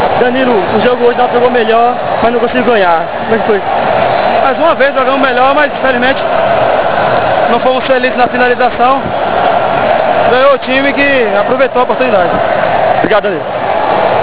ENTREVISTA *Em Audio